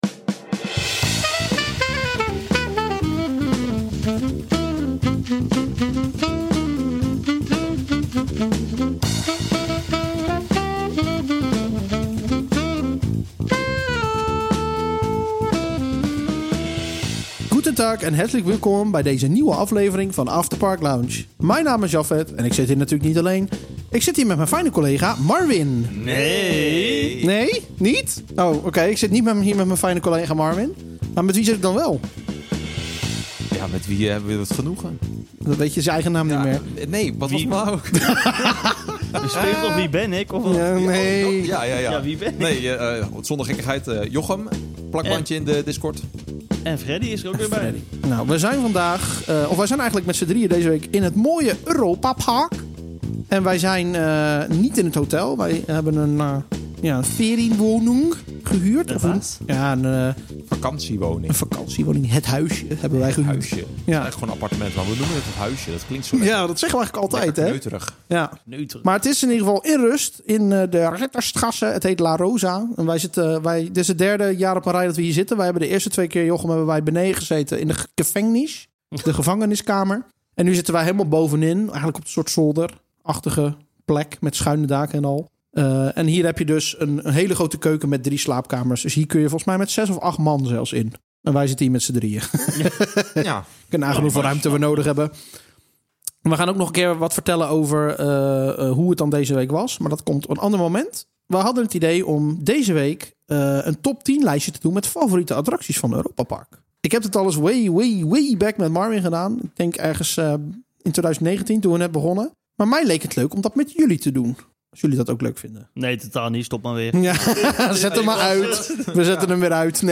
🎢 Favoriete attracties en verrassende keuzes 🏰 Veel liefde voor o.a. Piraten in Batavia, Wodan en Geisterschloss 🎙 Opgenomen vanuit het “huisje” tijdens een trip naar Europa-Park See all episodes